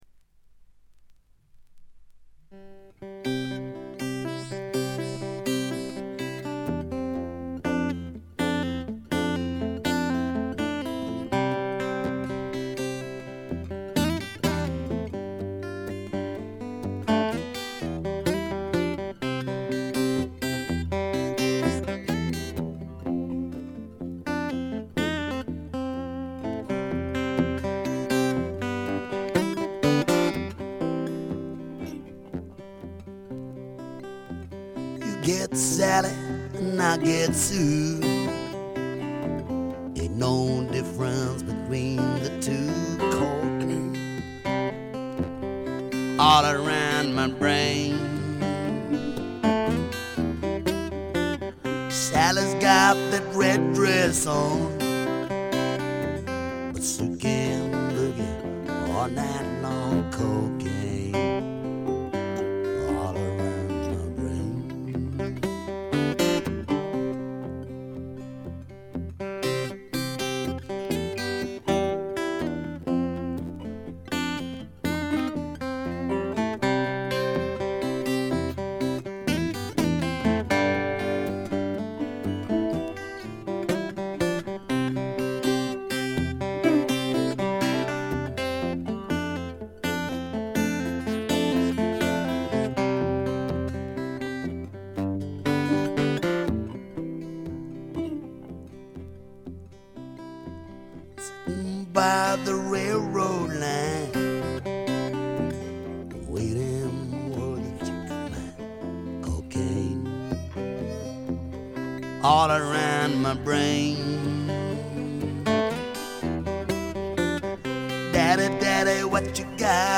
部分試聴ですがほとんどノイズ感無し。
内容は激渋のアコースティック・ブルース。
試聴曲は現品からの取り込み音源です。